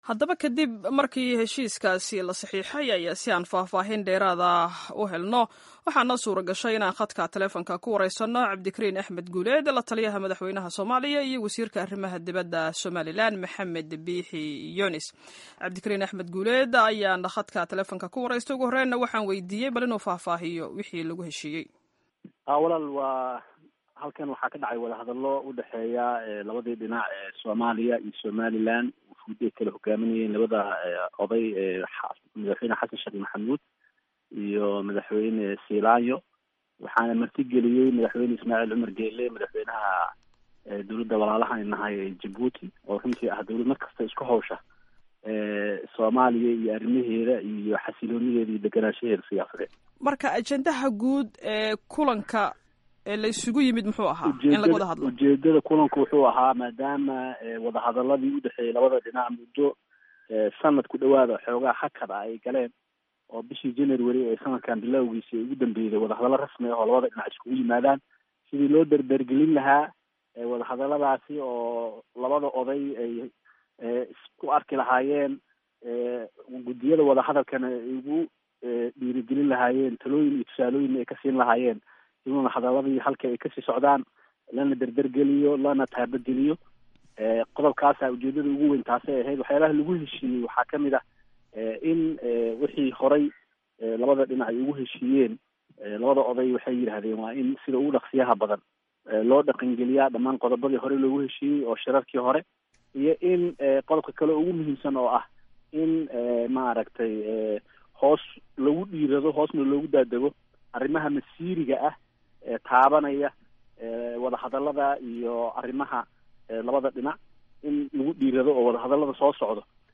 Waraysiyada La Taliyaha Madaxweynaha Somalia iyo Wasiirka Arimaha Debeda Somaliland